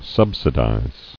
[sub·si·dize]